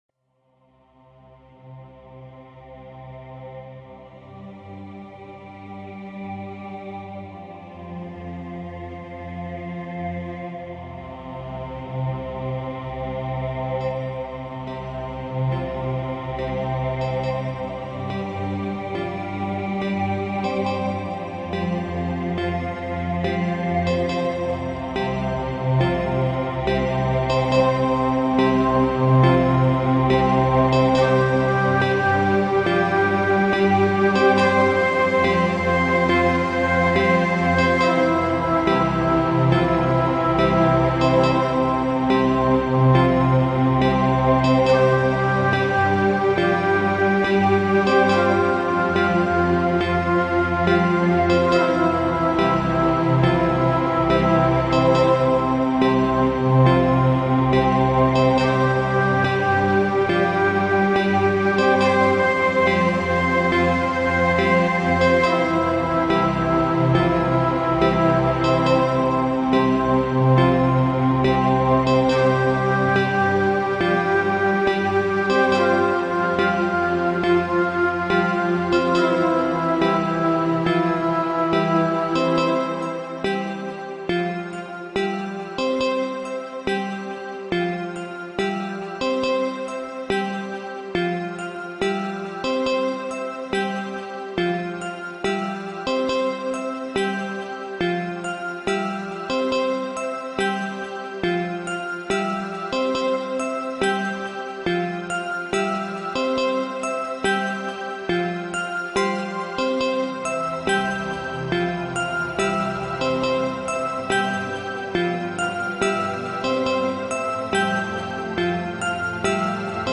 My attempt at a Pony theme song. Kinda soft. My first attempt at making a instrumental song.